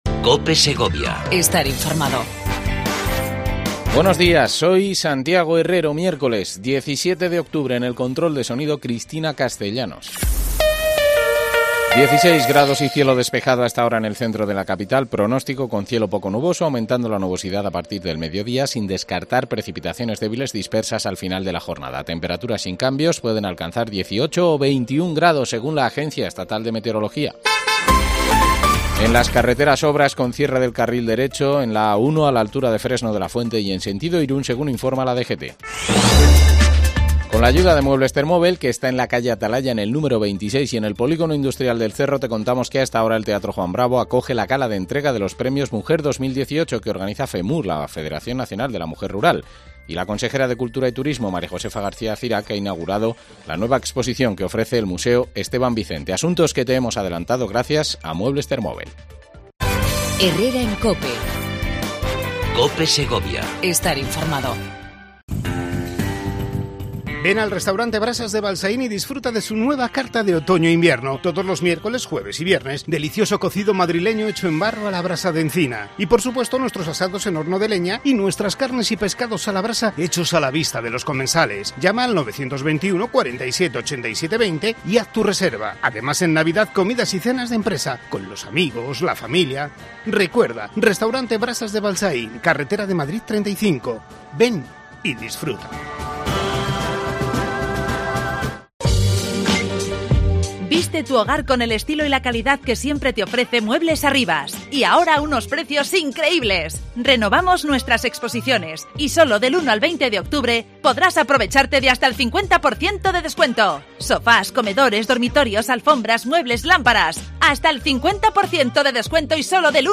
Entrevista a Natalia del Barrio, portavoz adjunta del grupo parlamentario de Podemos en las cortes regionales